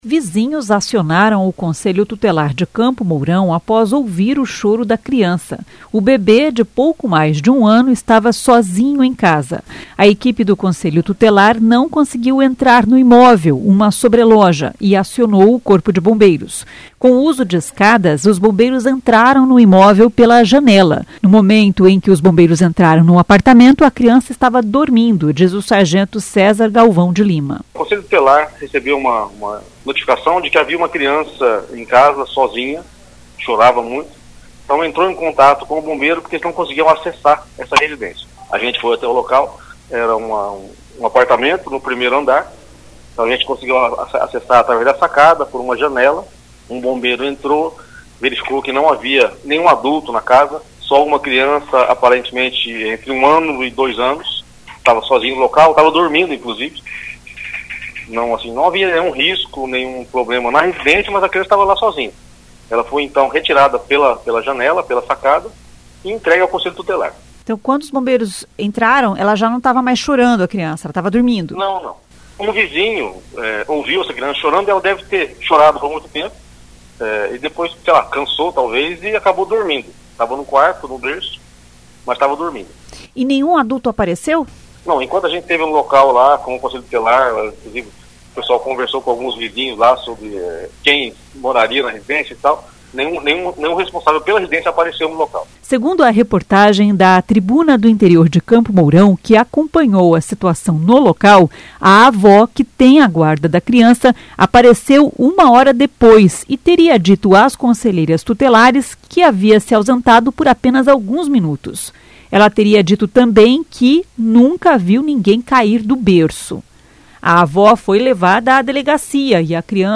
No momento em que os bombeiros entraram no apartamento, a criança estava dormindo, diz o sargento